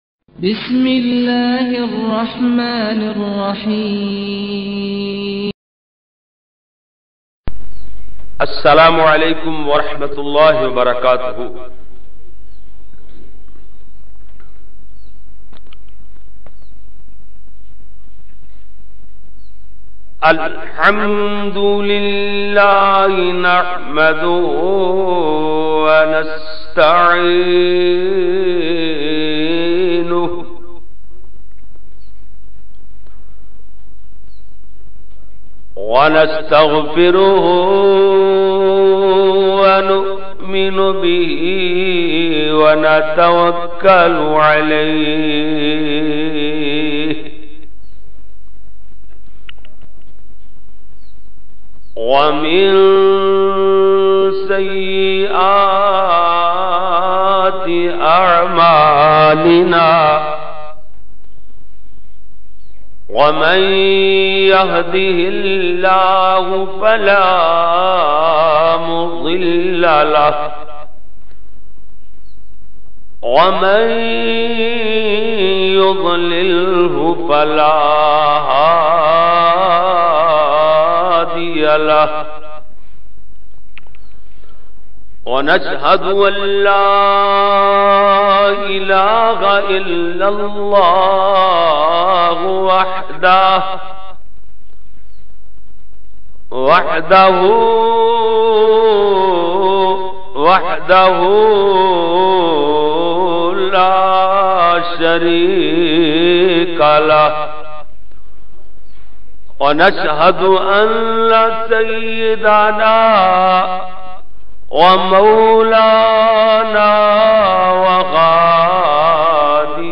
Eid Milad-un-nabi ki shari hesyt bayan mp3